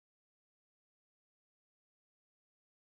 Pig Oink
Pig Oink is a free animals sound effect available for download in MP3 format.
295_pig_oink.mp3